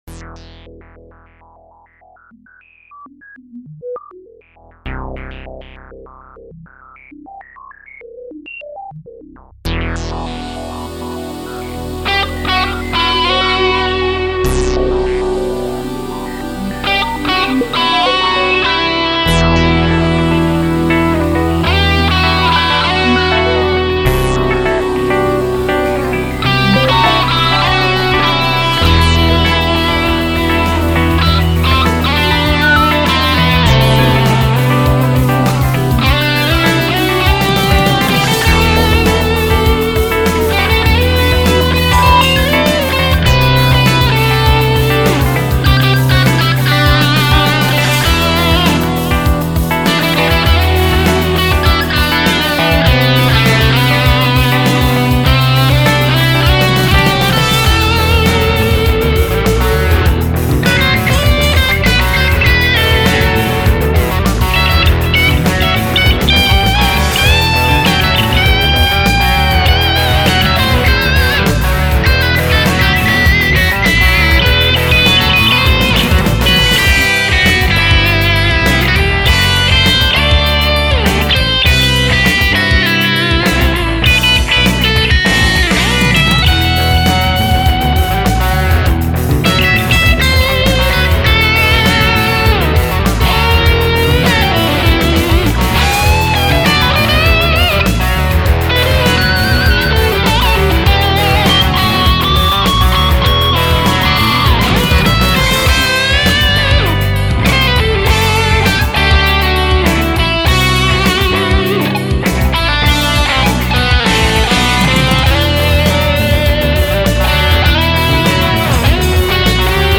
Das ist wieder die Junior mit dem Womanizer.